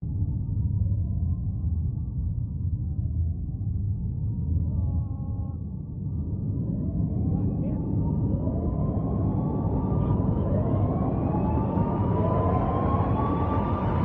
SFX
Steel Mill Emergency.mp3